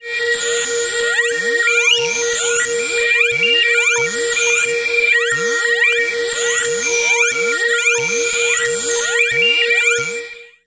标签： ASharp5 MIDI音符-82 Korg的-Z1 合成器 单票据 多重采样
声道立体声